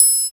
59 TRIANGLE.wav